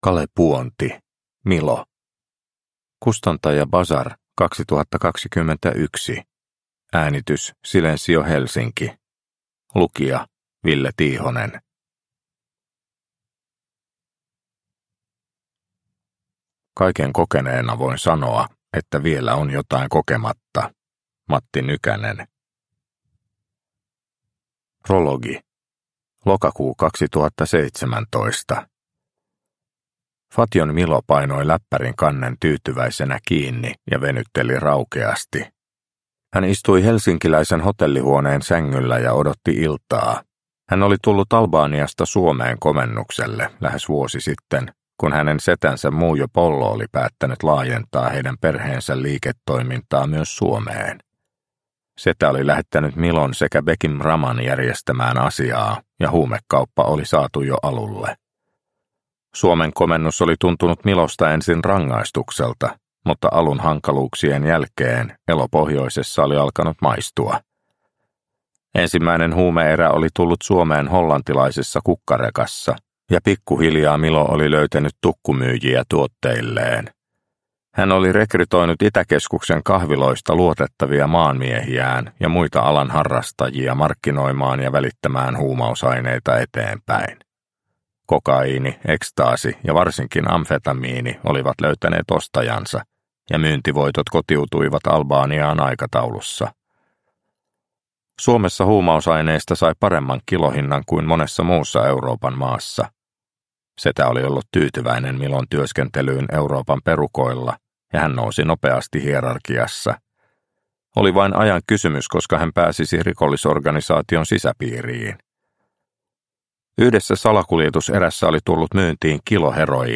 Milo – Ljudbok – Laddas ner